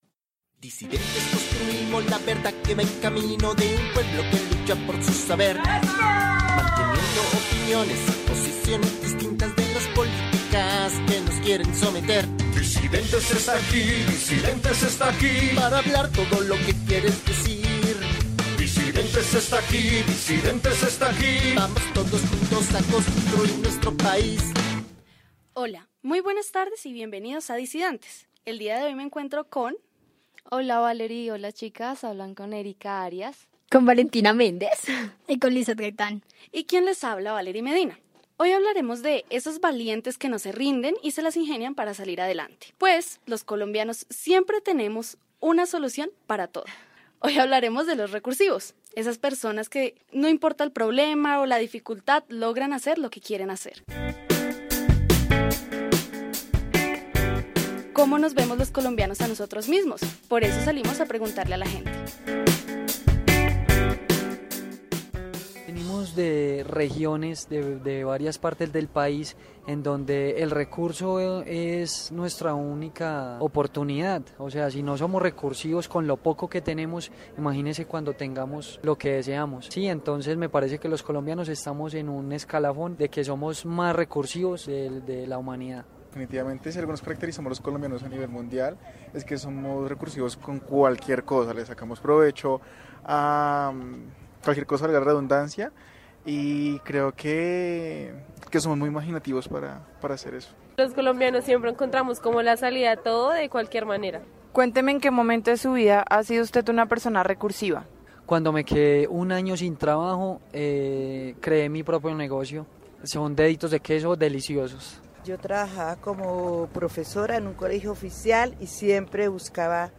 El programa gira en torno a vivencias y experiencias que nos cuentan los invitados.